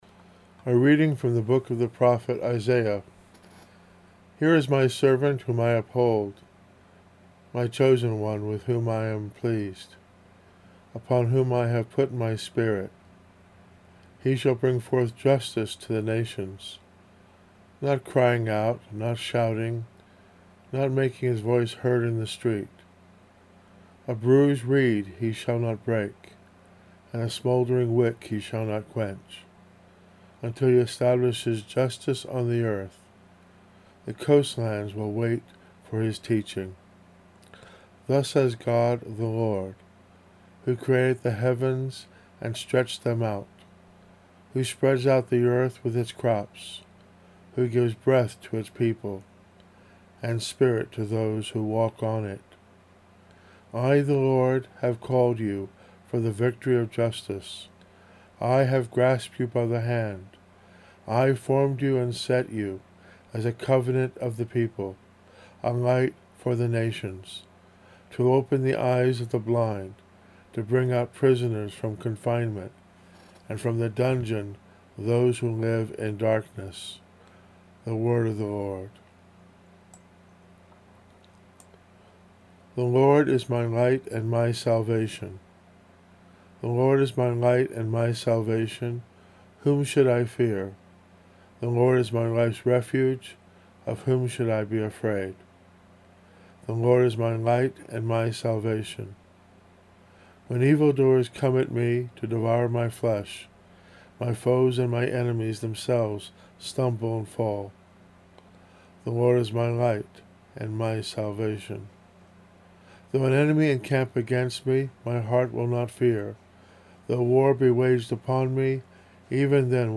Homily: